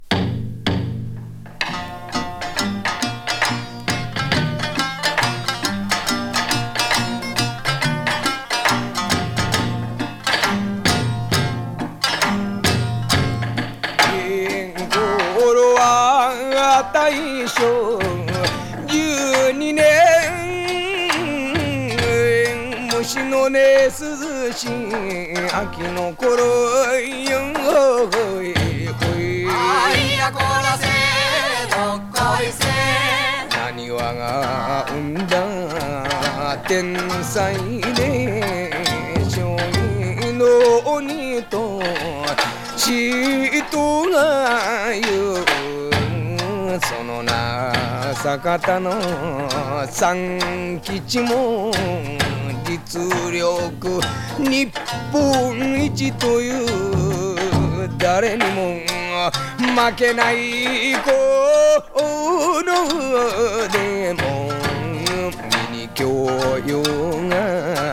河内音頭特有の節回しがクセになります。